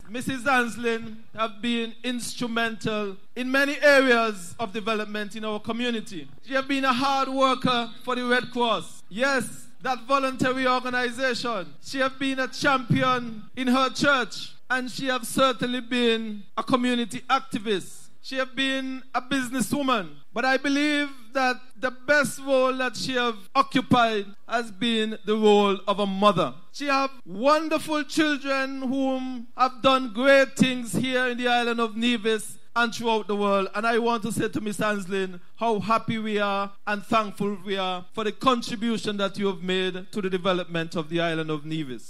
“Christmas in the Capital” began the scenes of Christmas cheer as the Christmas Tree Lighting Ceremony took place on Wednesday night at the War Memorial Square in Charlestown.